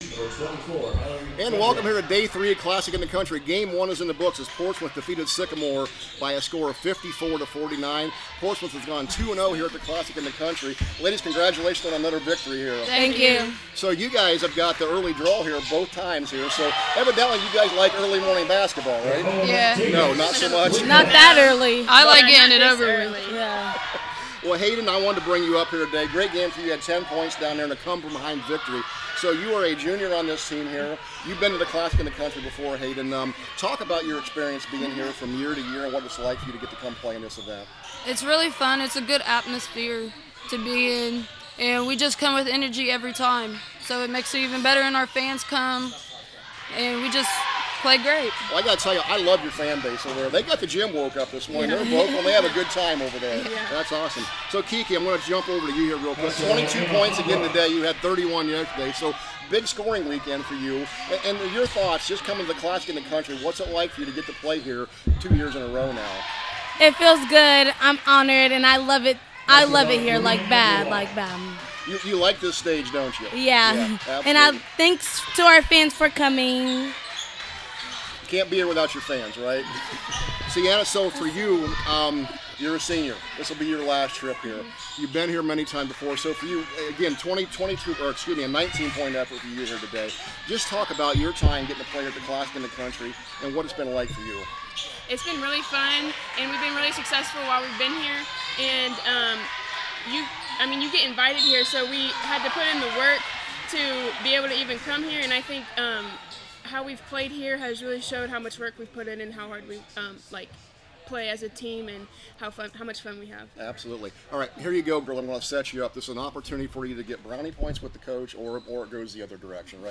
2026 CLASSIC – PORTSMOUTH PLAYERS INTERVIEW